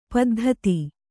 ♪ paddhati